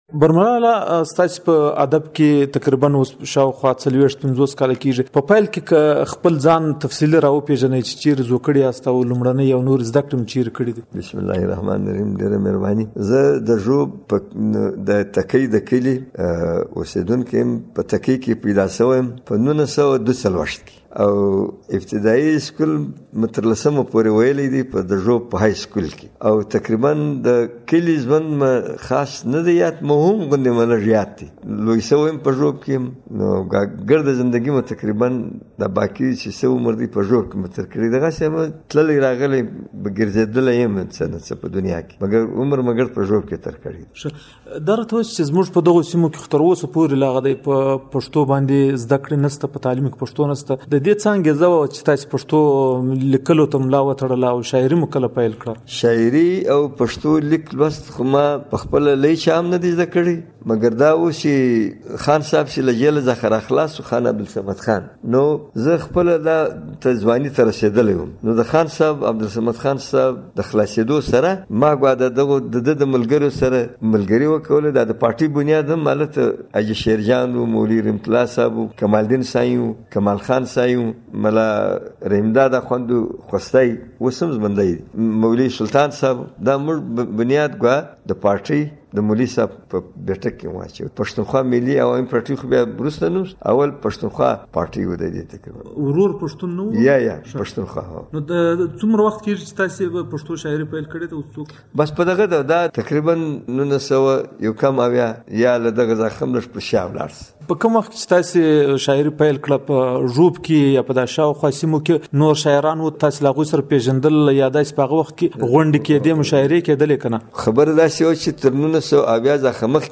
دا د مشال راډیو د ځانګړي بحث او شننو اوونیزې خپرونې پاڼه ده.